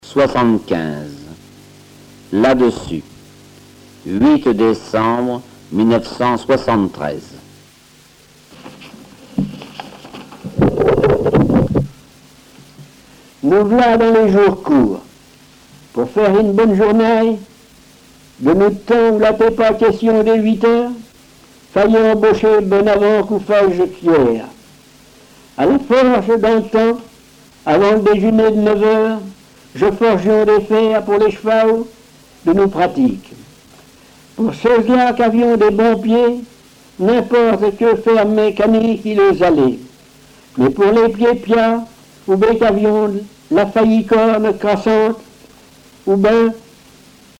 Langue Patois local
Genre récit
Récits en patois